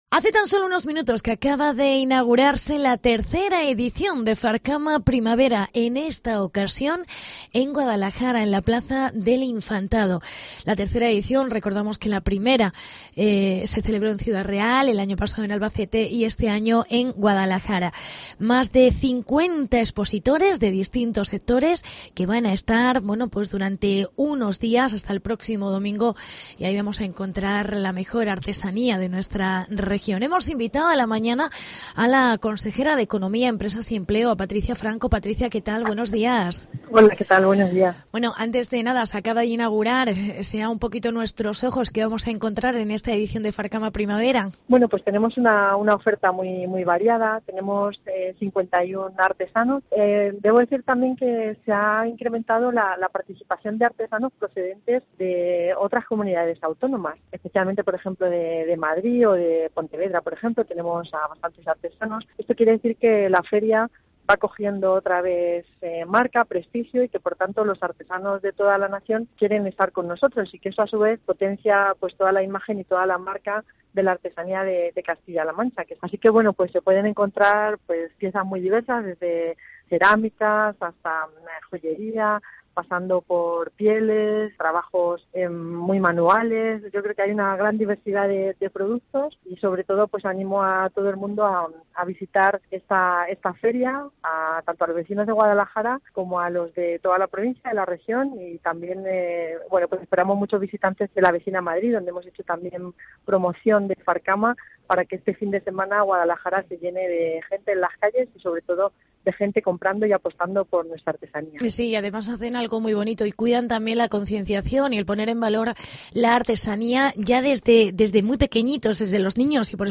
Entrevista con la Consejera Patricia Franco